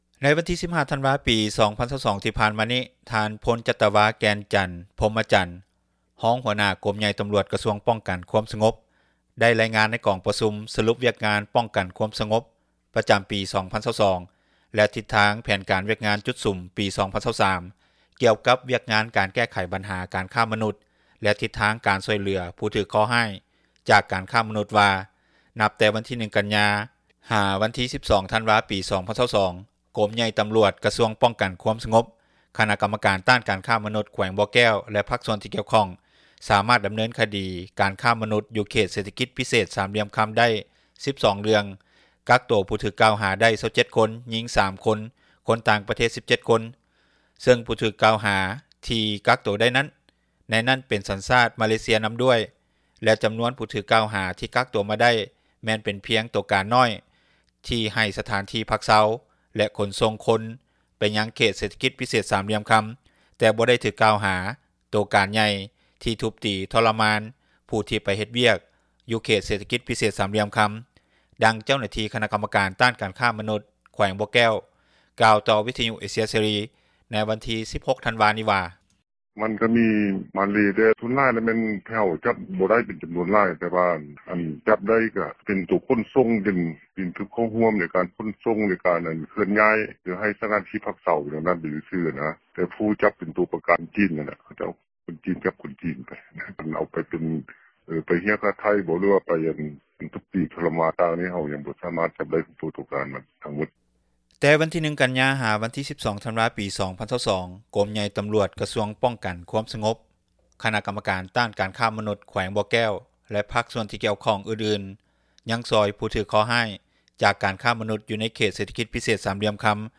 ດັ່ງອະດີຕ ຄົນງານຕອບແຊັດ ຢູ່ເຂດເສຖກິຈພິເສດ ສາມຫລ່ຽມຄຳ ກ່າວໃນມື້ດຽວກັນນີ້ວ່າ: